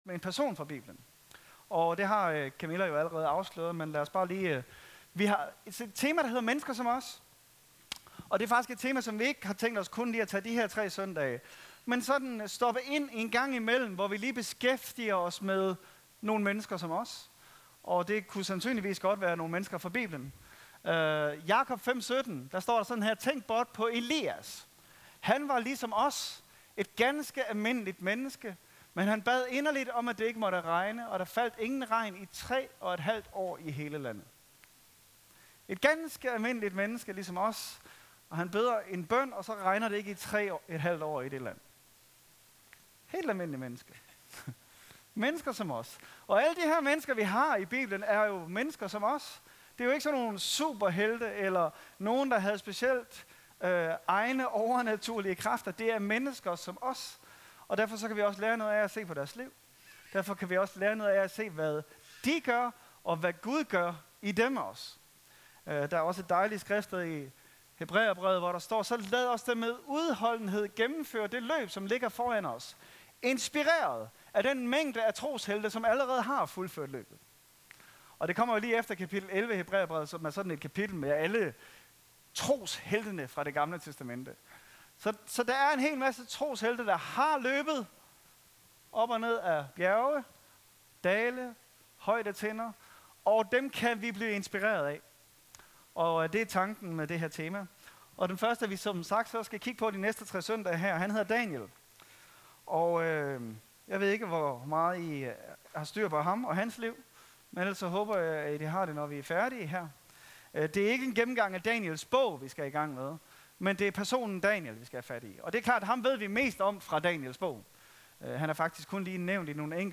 En prædiken fra tema "Mennesker som os."